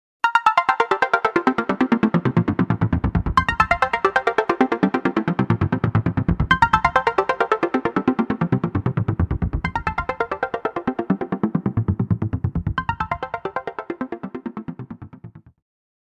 Ein Arpeggiator "bricht" einen gegriffenen Akkord, d.h. er zerlegt ihn in Einzeltöne, die mit einstellbarer Notenlänge hintereinander wiedergegeben werden.
Arpeggio Down
arp_dwn.mp3